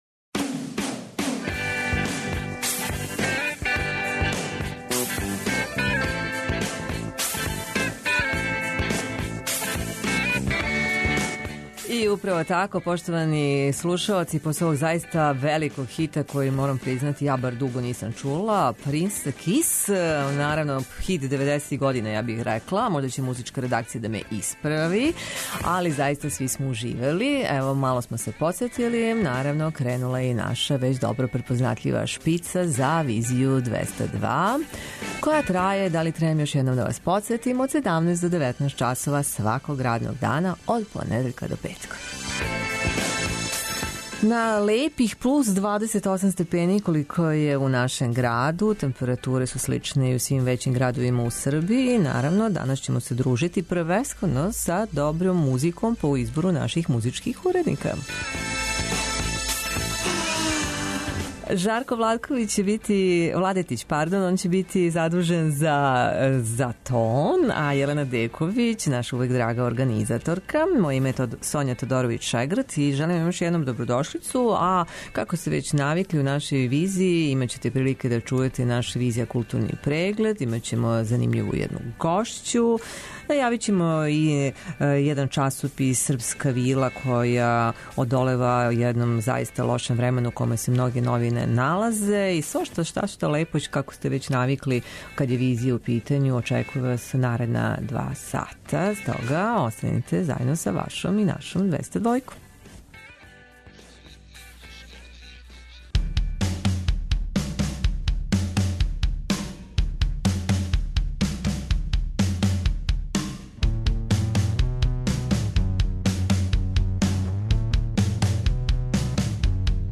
преузми : 55.39 MB Визија Autor: Београд 202 Социо-културолошки магазин, који прати савремене друштвене феномене.